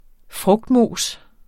Udtale [ -ˌmoˀs ]